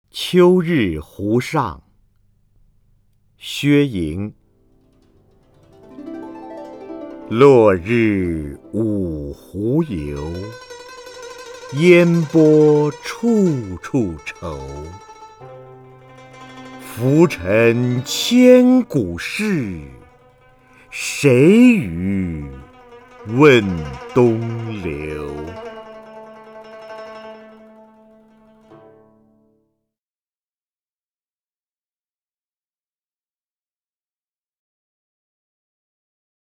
瞿弦和朗诵：《秋日湖上》(（唐）薛莹) （唐）薛莹 名家朗诵欣赏瞿弦和 语文PLUS